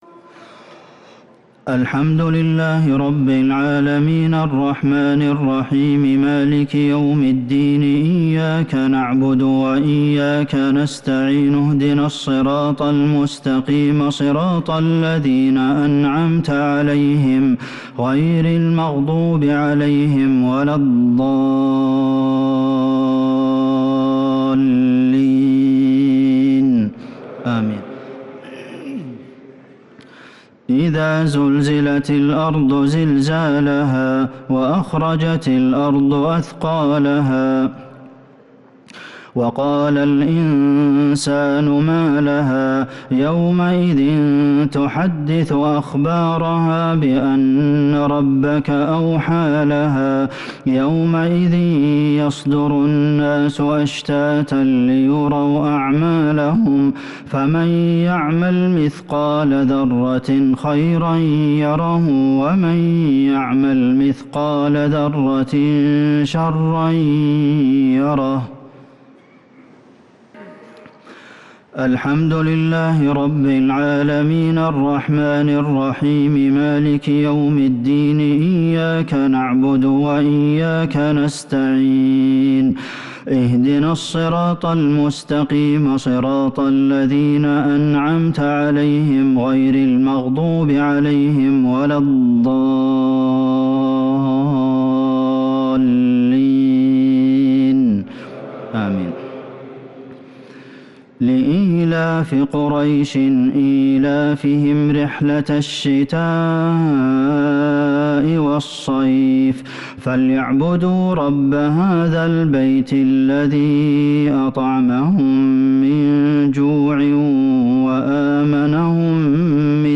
الشفع و الوتر ليلة 11 رمضان 1443هـ | Witr 11st night Ramadan 1443H > تراويح الحرم النبوي عام 1443 🕌 > التراويح - تلاوات الحرمين